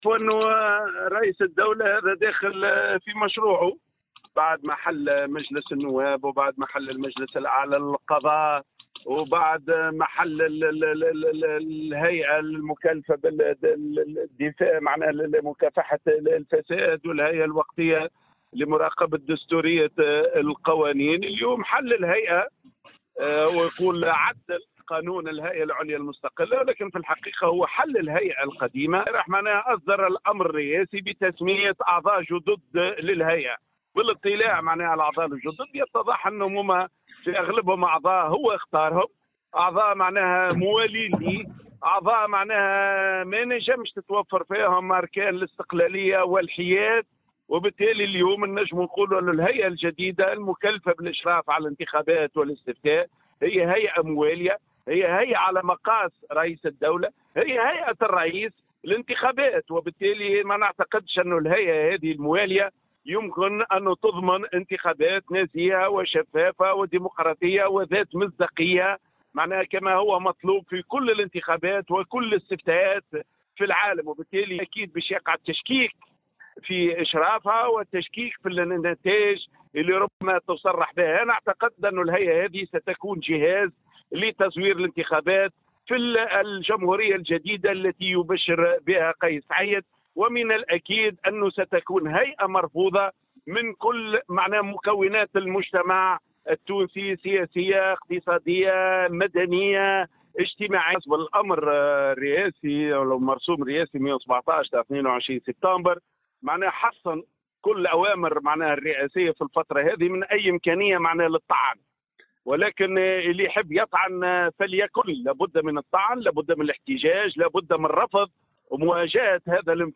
Dans une déclaration accordée aujourd’hui à Tunisie Numérique, le secrétaire général du Courant démocrate Ghazi Chaouachi a commenté la nouvelle composition de l’ISIE mise en place sur ordre présidentiel.